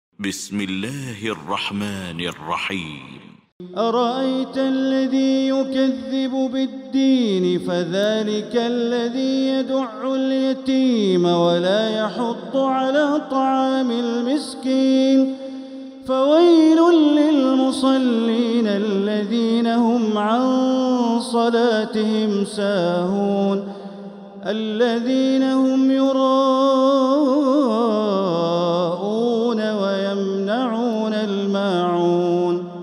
المكان: المسجد الحرام الشيخ: معالي الشيخ أ.د. بندر بليلة معالي الشيخ أ.د. بندر بليلة الماعون